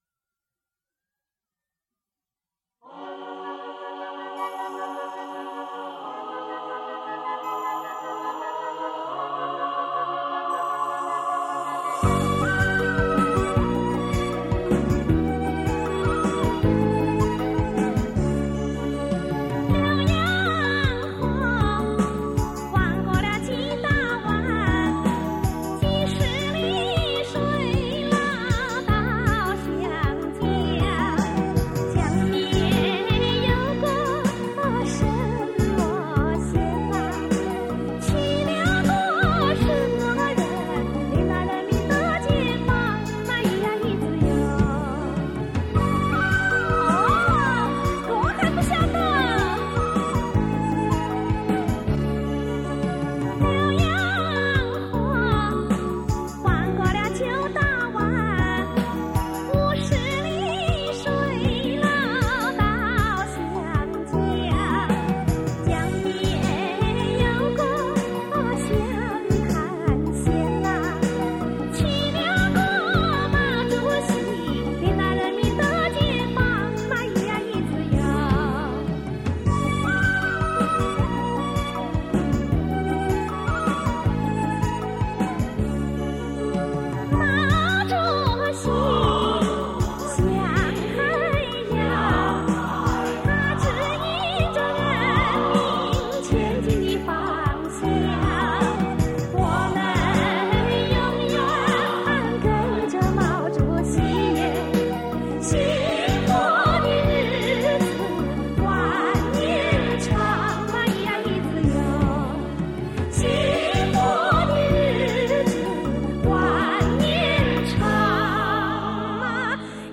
这个系列的磁带制作精良，采用了国内磁带很少使用的杜比B NR及HX PRO技术，音响效果非常不错。
磁带数字化